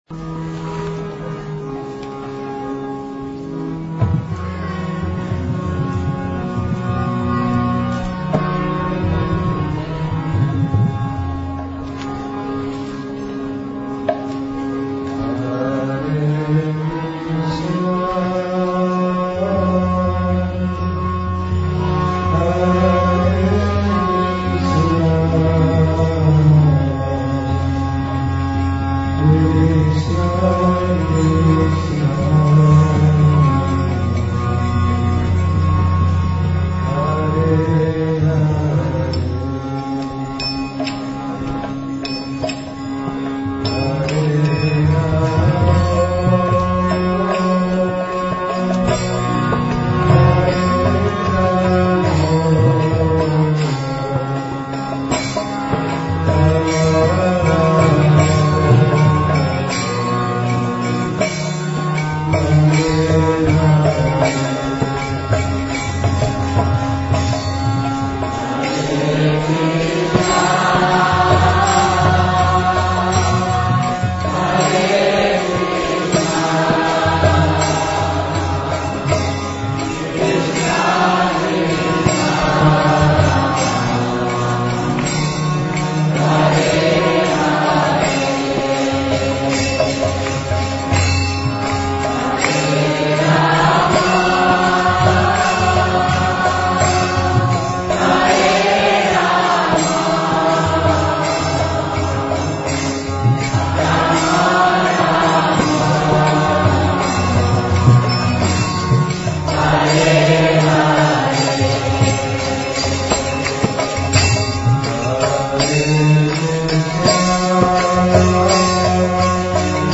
Hari Nama Yajna